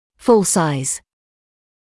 [ful saɪz][фул сайз]полноразмерный